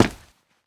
Run6.ogg